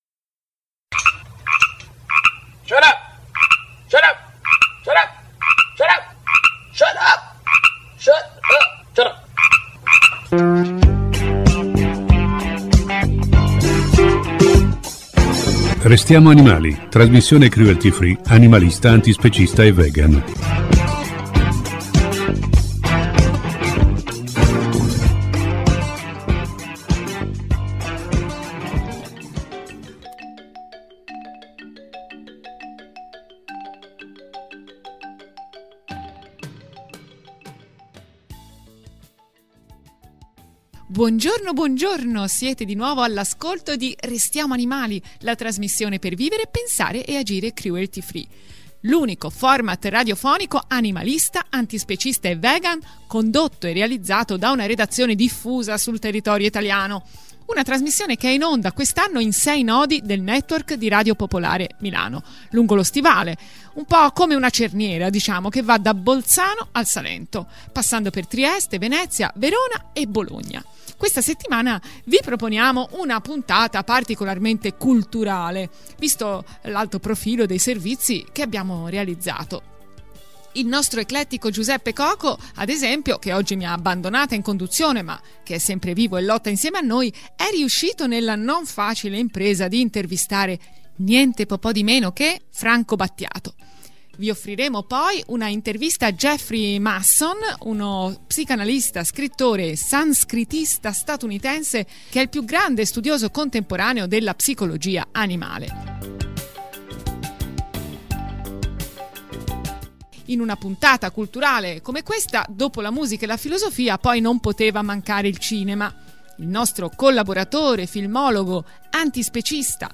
Grazie a un’intervista esclusiva